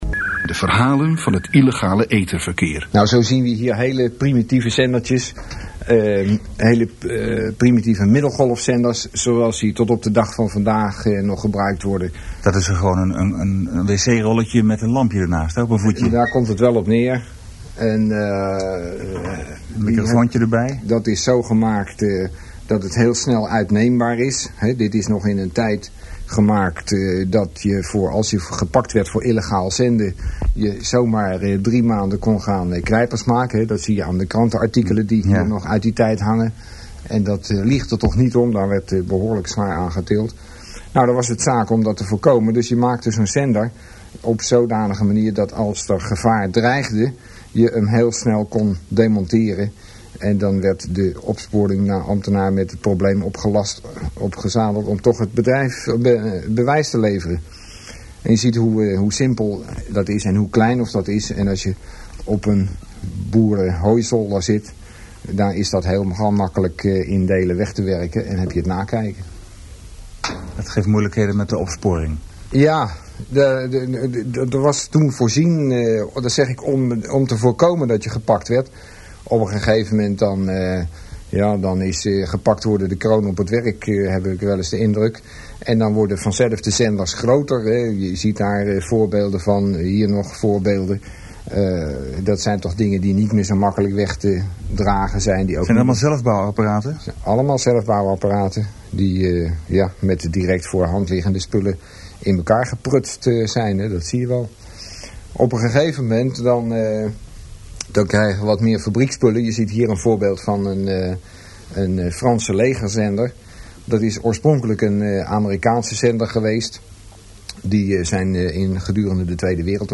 �Interview met een Opsporingsambtenaar van de RCD�